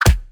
PUNCH_PERCUSSIVE_HEAVY_09.wav